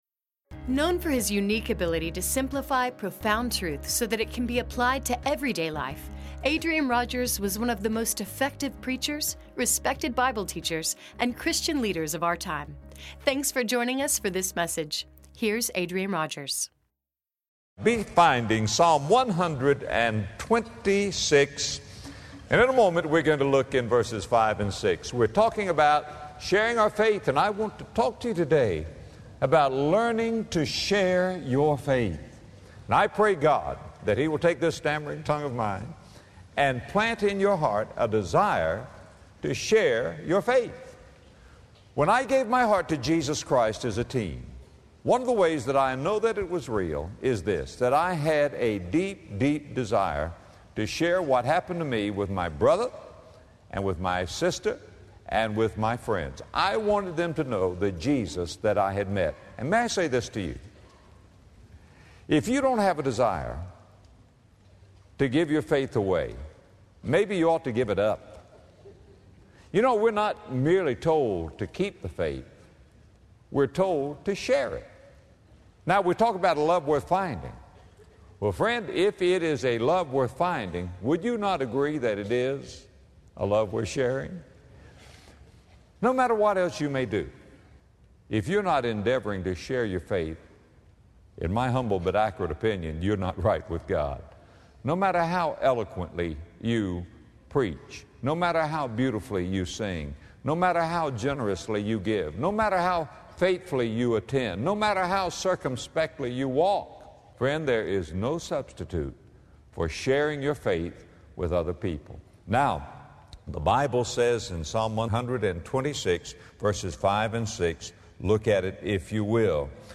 No matter how generous we are, how active we are in church, or how eloquently we preach, there is no substitute for sharing our faith with other people. In this message, Adrian Rogers teaches us how to share Jesus, as instructed in Psalm 126.